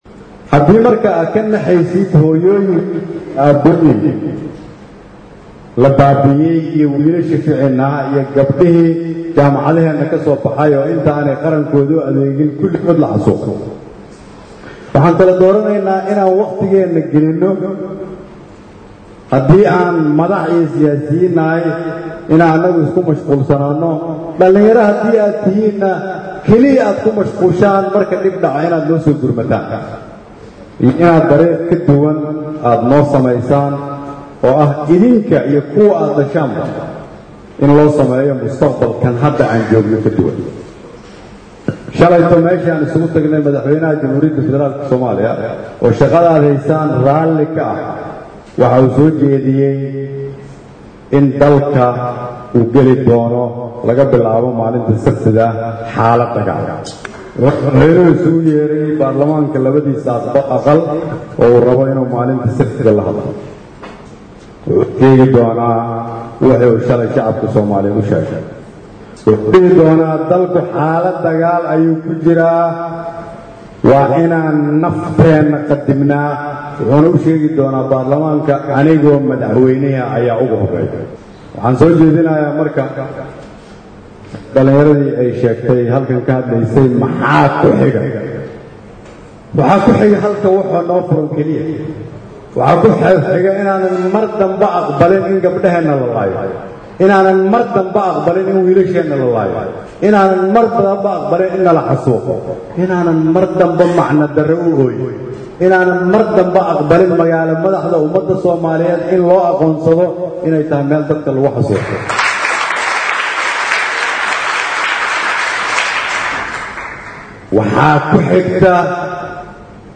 Xasan Khayre ayaa Dhalinyarada Soomaaliyeed gaar ahaan kuwa Gurmad Qaran oo maanta Qado sharaf uu u sameeyay ayuu ugu baaqay in sida hada ay isugu duuban yihiin ay isugu xirnaadaan.